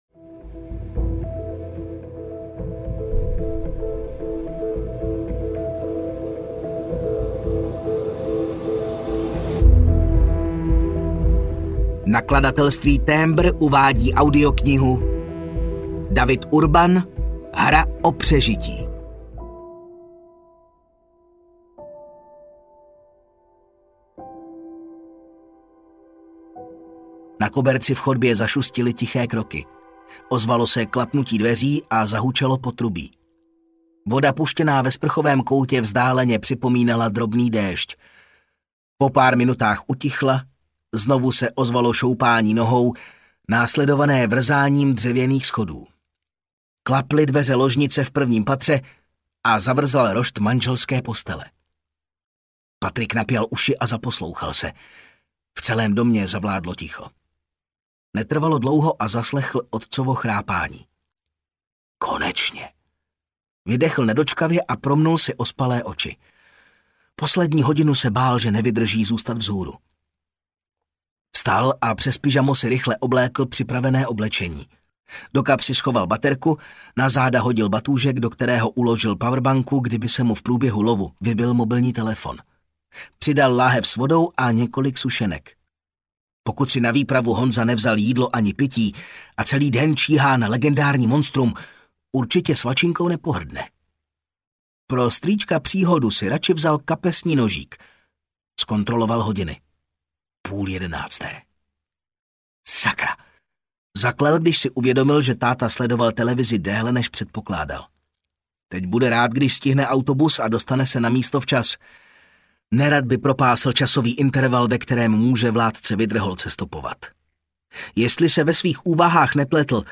Hra o přežití audiokniha
Ukázka z knihy